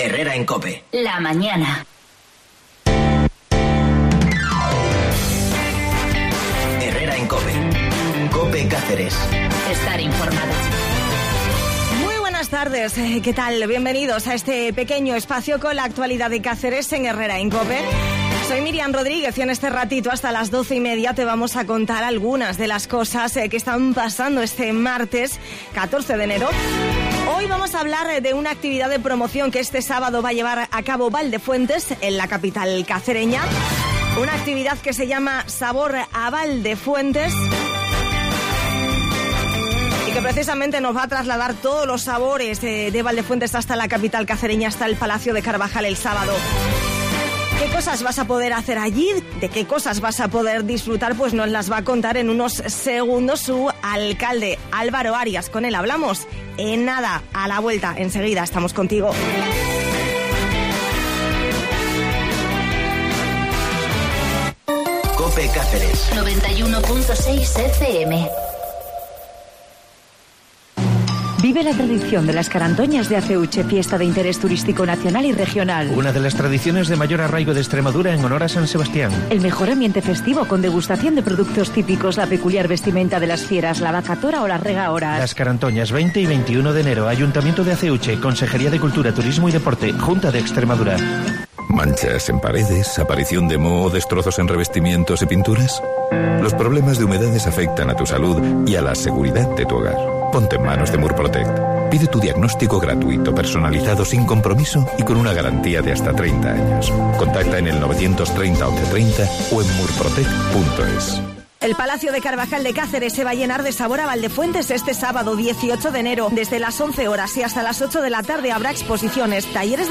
En Herrera en Cope hablamos de esta actividad con el alcalde de la localidad, Álvaro Arias.